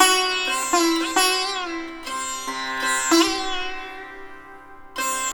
100-SITAR3-R.wav